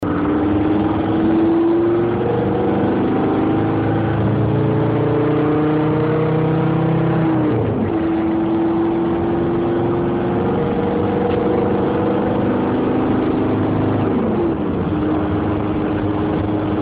312D_Beschleunigen_Autobahn
312D_Beschleunigen_Autobahn.mp3